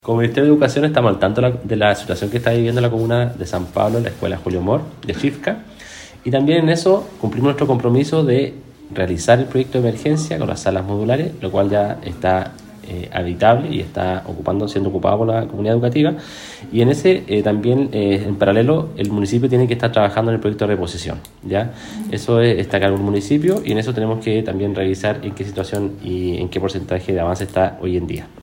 Por su parte, el seremi de Educación en Los Lagos, Juan Gómez, expresó que están al tanto del problema.